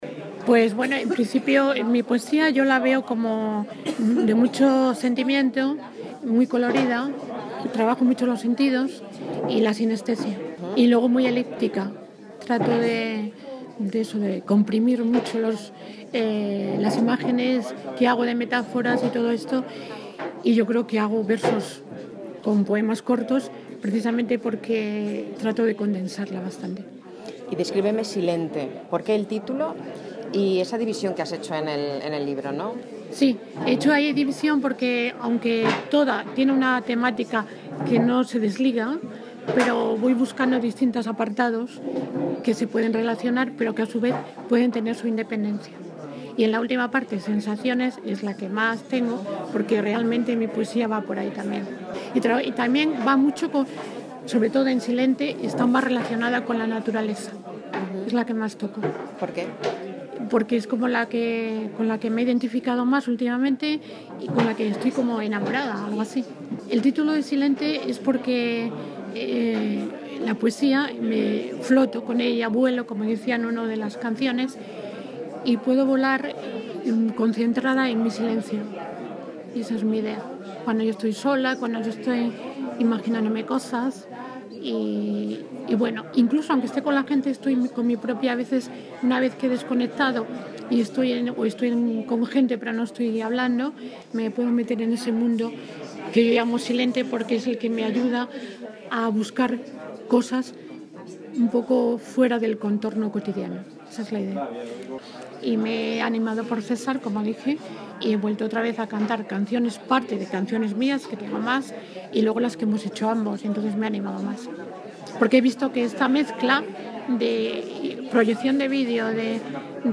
tras el recital